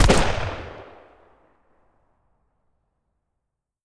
Revolver_Shoot 03.wav